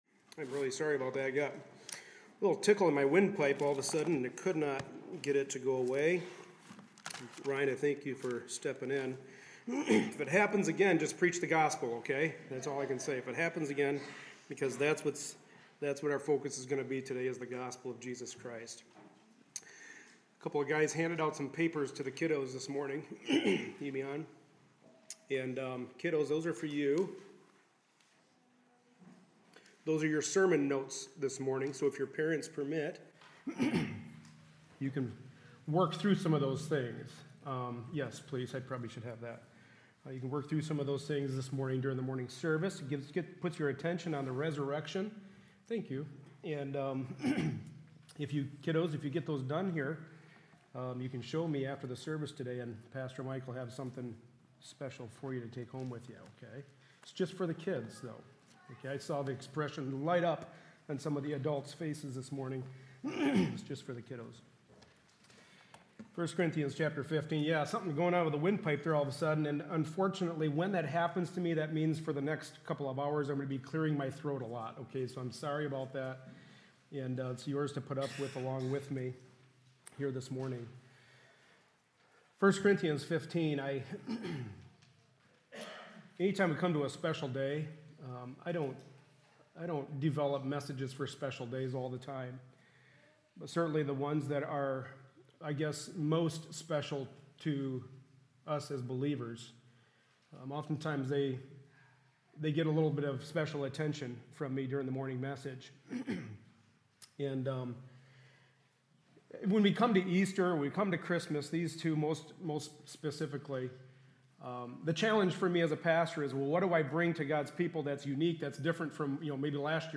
Passage: 1 Corinthians 15:1-4 Service Type: Sunday Morning Service Resurrection Sunday’s message.